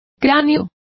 Complete with pronunciation of the translation of skull.